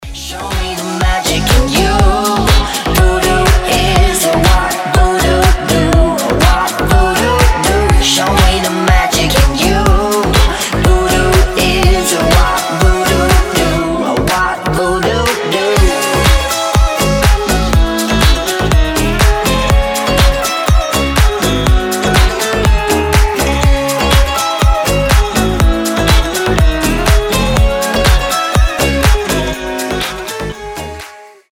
• Качество: 320, Stereo
гитара
deep house
зажигательные
заводные
озорные
Заводной и озорной рингтон